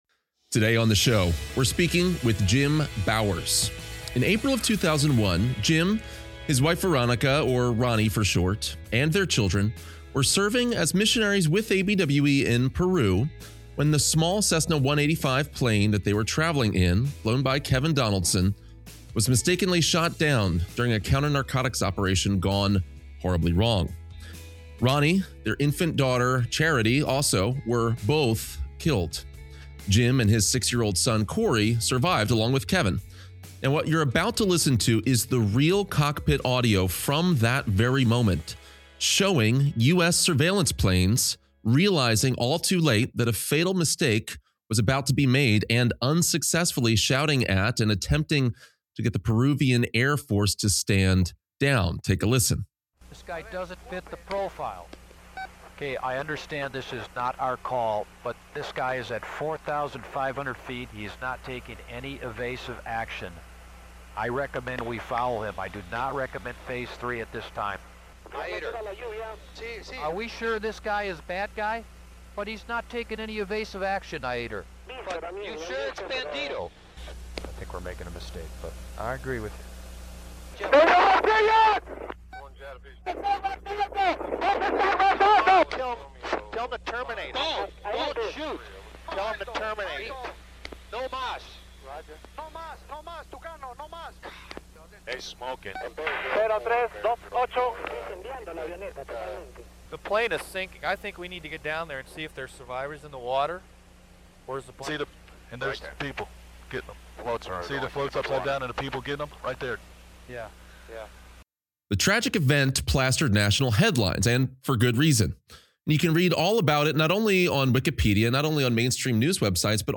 Why does God give us a glimpse of the future? How can we prepare for suffering in missions? This week, hear a special presentation